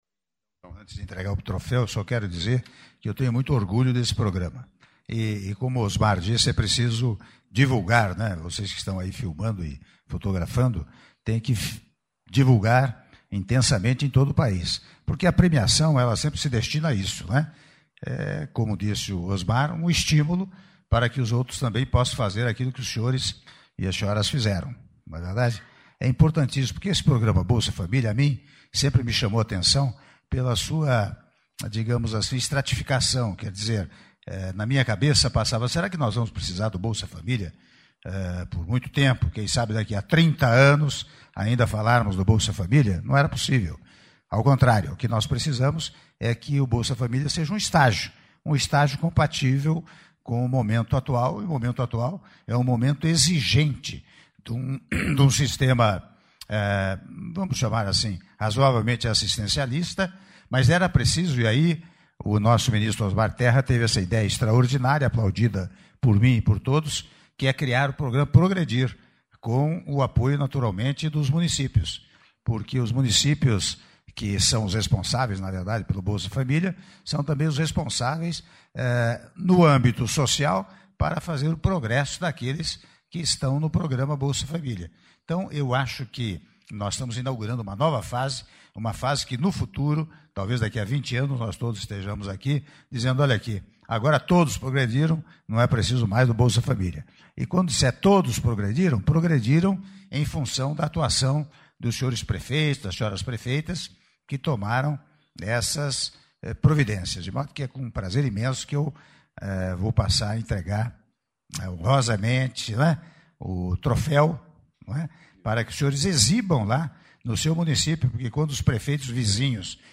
Áudio do discurso do Presidente da República, Michel Temer, durante Entrega de Troféus aos Vencedores do Prêmio Progredir - (02min24s) - Brasília/DF